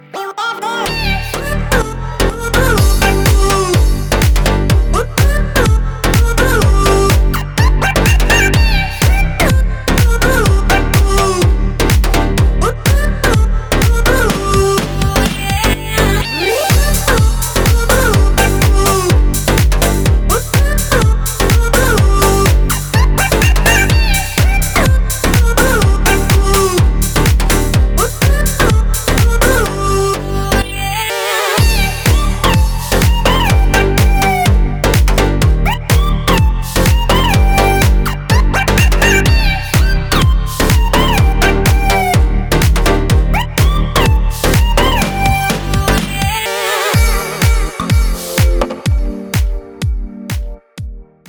• Качество: 320, Stereo
deep house
веселые
Electronic
EDM
качающие